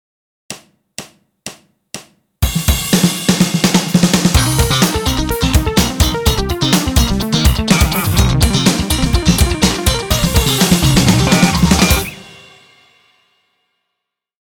ブルース系ソロ2 ハイテク系ファンク風アプローチ